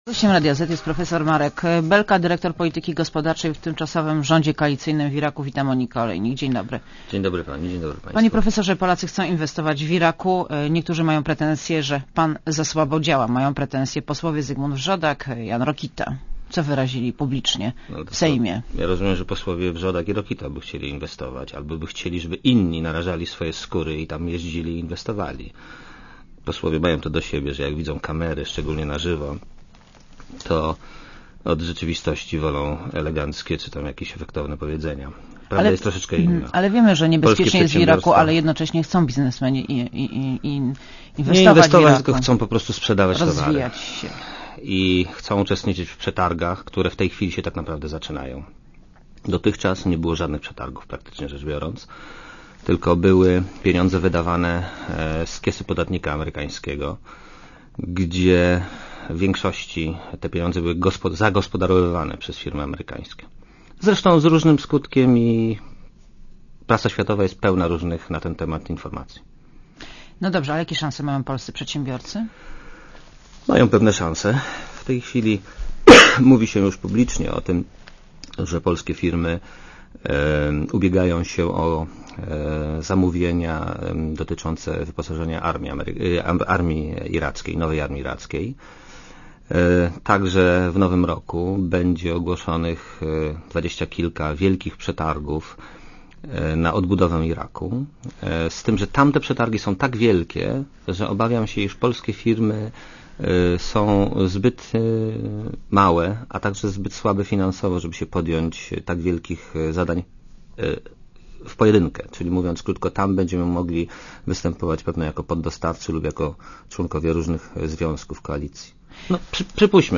Posłuchaj wywiadu - 2.6 MB A gościem Radia Zet jest prof. Marek Belka , dyrektor polityki gospodarczej w Tymczasowym Rządzie Koalicyjnym w Iraku.